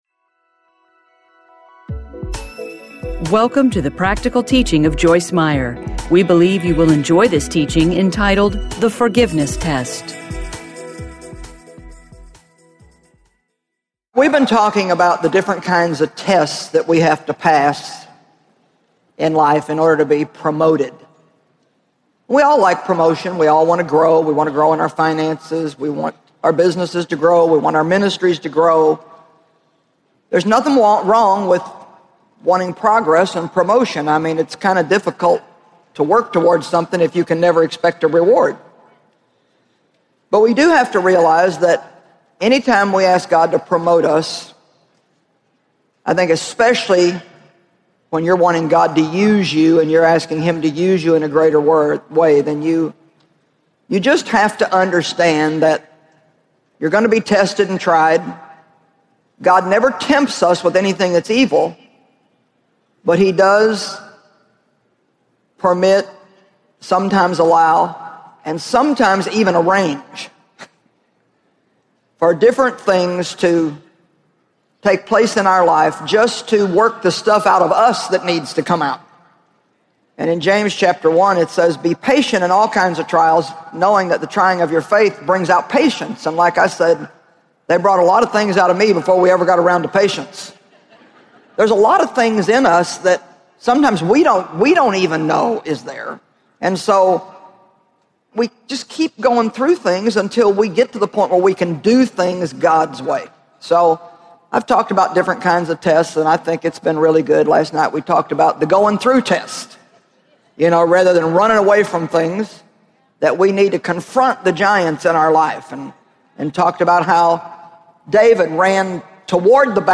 Don’t Panic! Teaching Series Audiobook